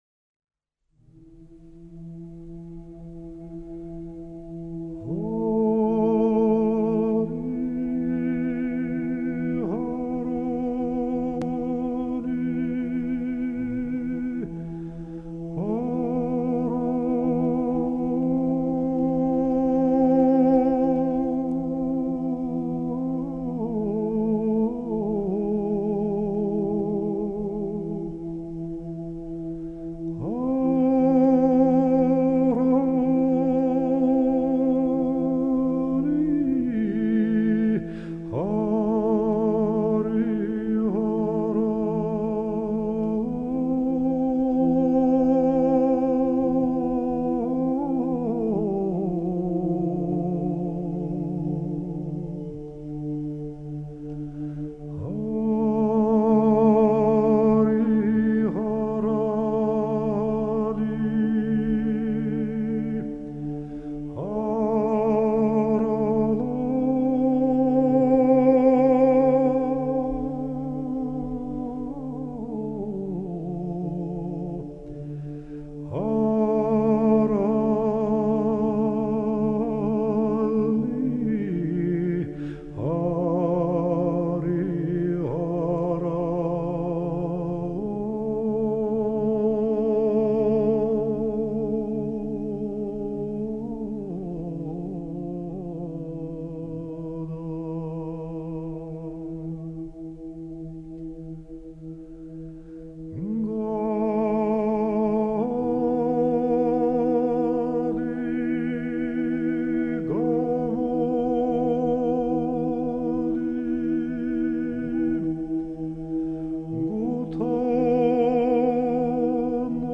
Rustavi_orovela.ogg